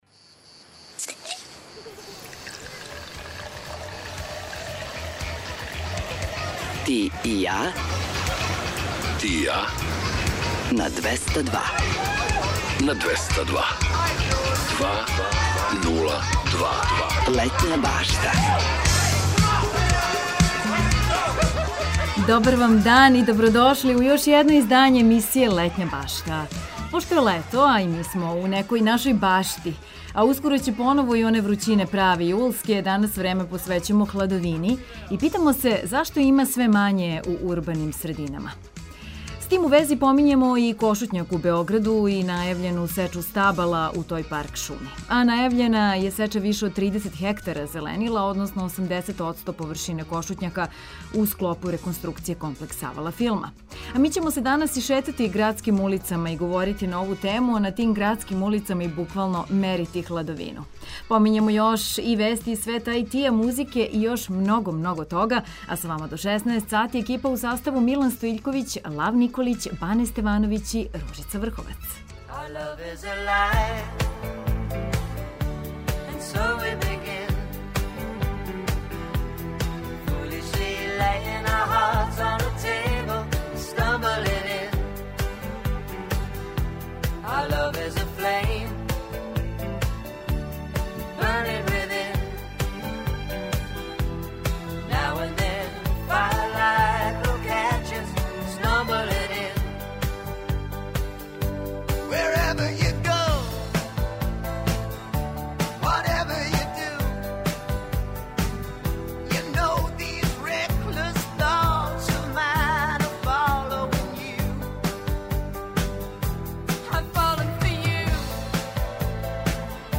У наставку програма се шетамо „зеленим” улицама престонице као и оним улицама у центру града које су потпуно без хладовине. Припремили смо за вас и Приче о песмама , пола сата „домаћица” - музике из Србије и региона, а помињемо и догађаје из света музике који су обележили данашњи дан.